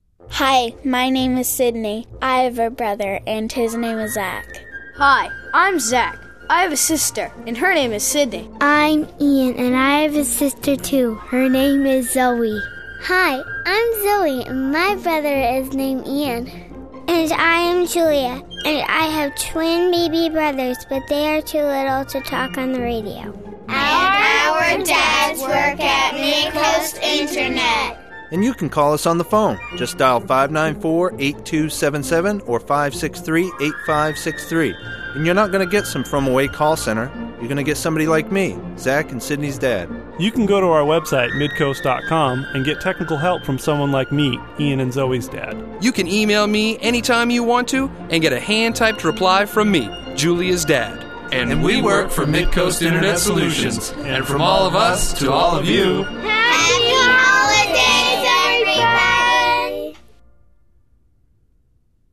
New MIS Radio Ad